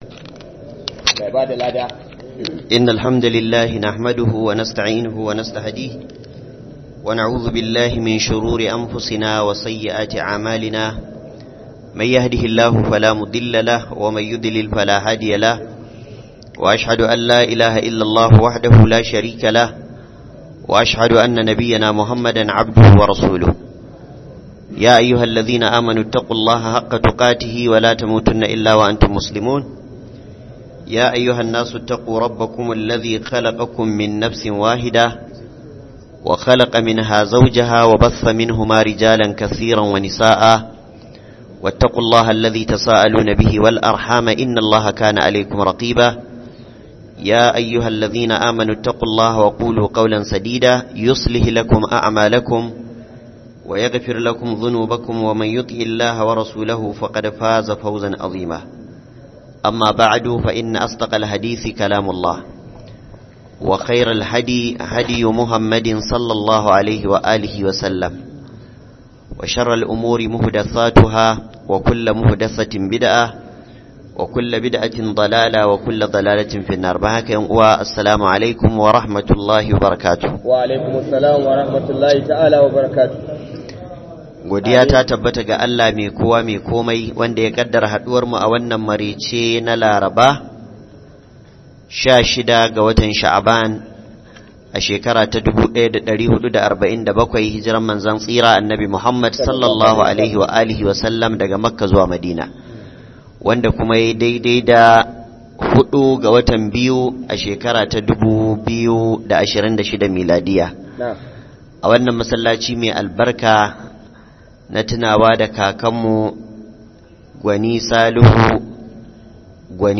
MARABA DA RAMADAN 2026-02-05 - MUHADARA